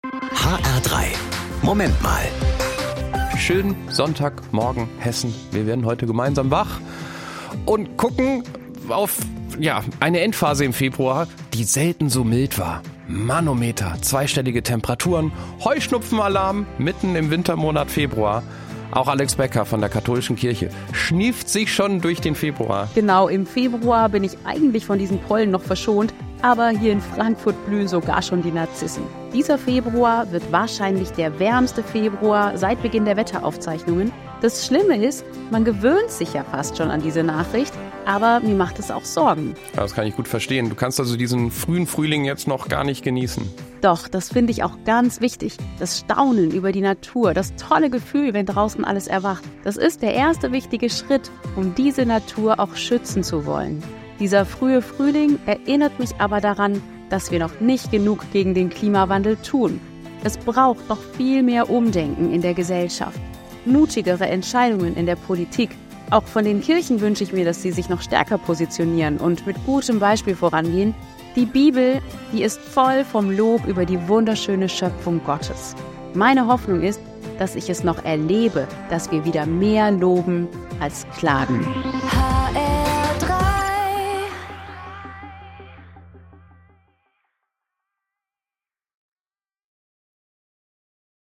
Katholische Pastoralreferentin Frankfurt